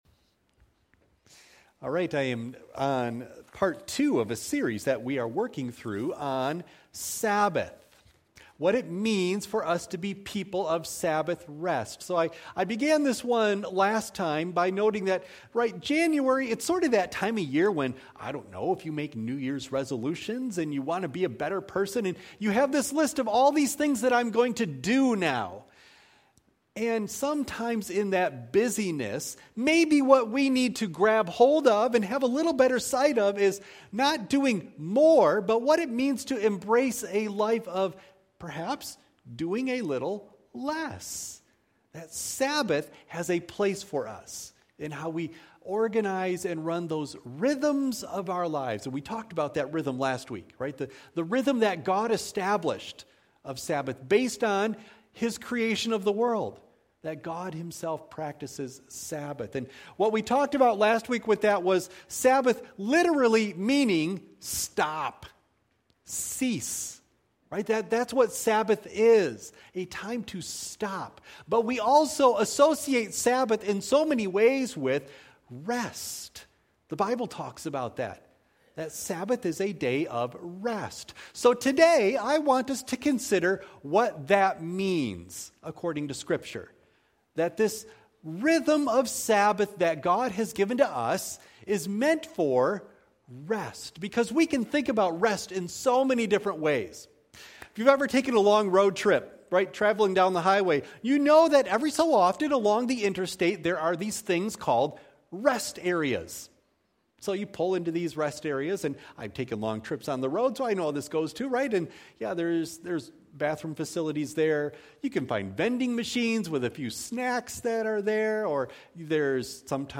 Matthew 11:28-12:8 Worship Service Video January 12 Audio of Message Download Files Notes Bulletin « Sabbath